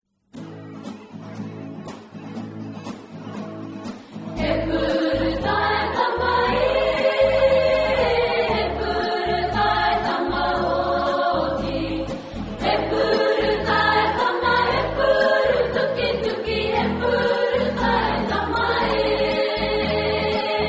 Twee liederen, gezongen door Maori vrouwen